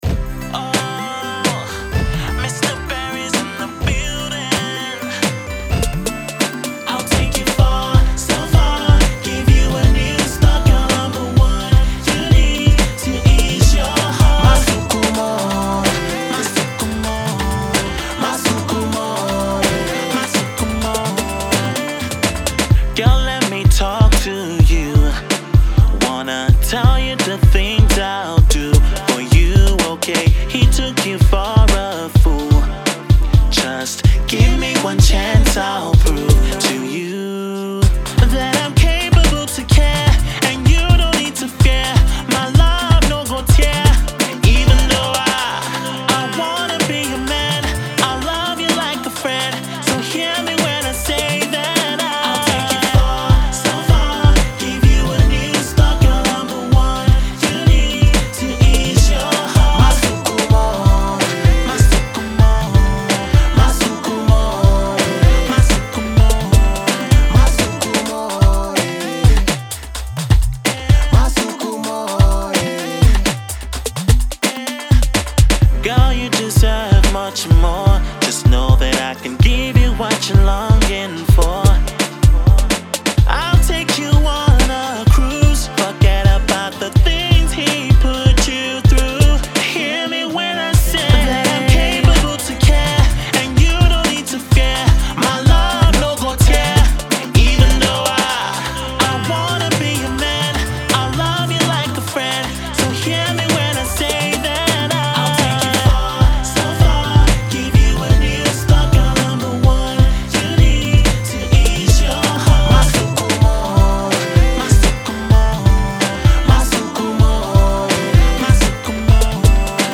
touching love song